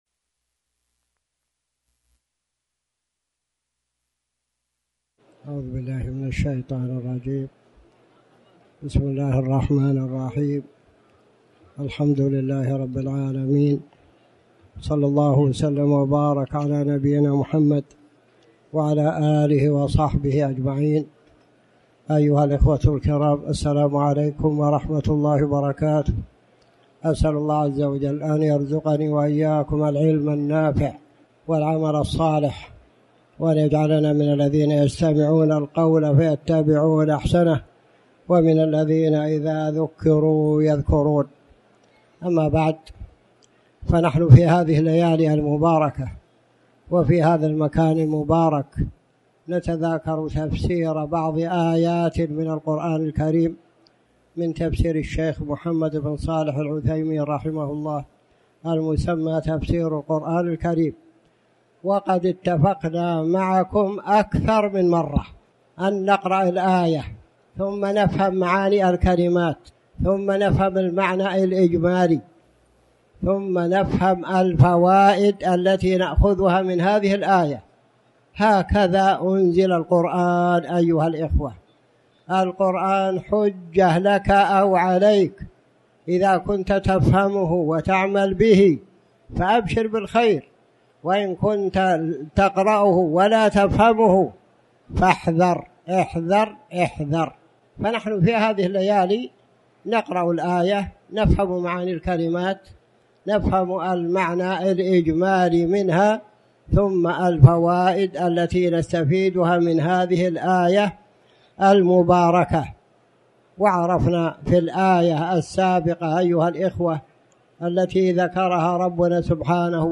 تاريخ النشر ١٩ رمضان ١٤٣٩ هـ المكان: المسجد الحرام الشيخ